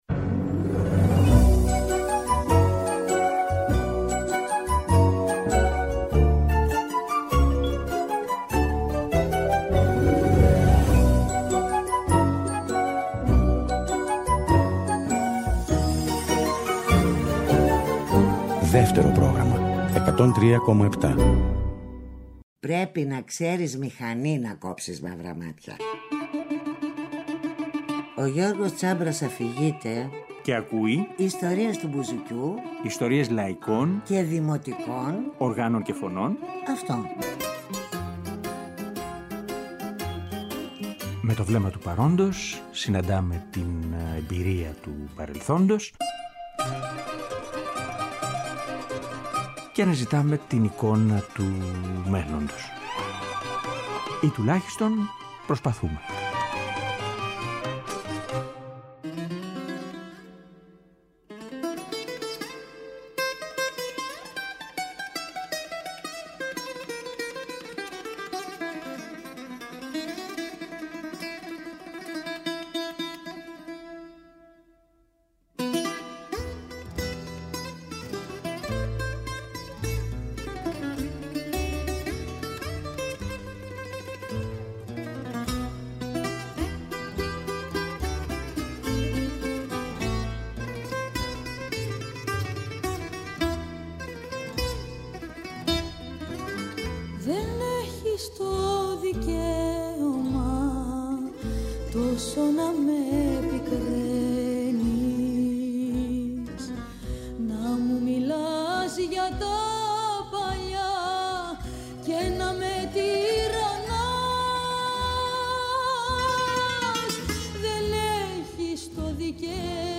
Στην εκπομπή μιλάμε για όλο αυτό το ταξίδι, ακούγοντας ηχογραφήσεις που παίζει ή τραγουδά, αλλά και κάποιες δικές του δημιουργίες.